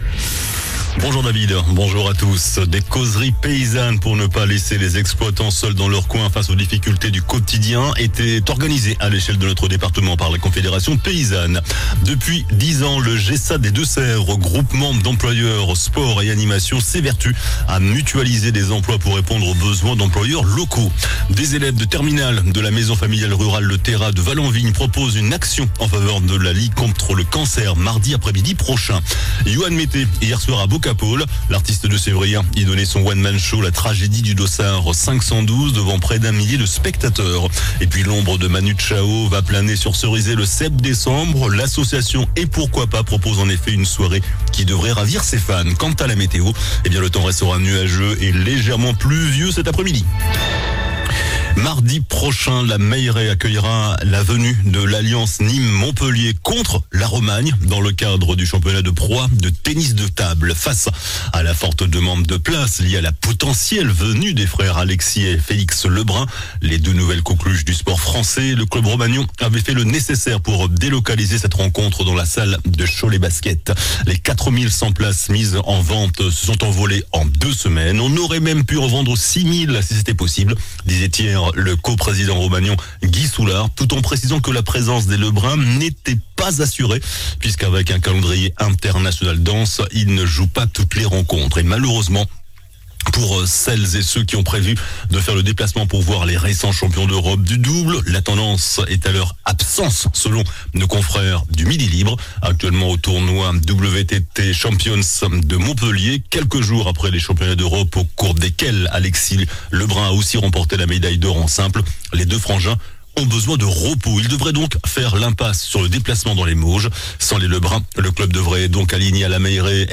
JOURNAL DU VENDREDI 25 OCTOBRE ( MIDI )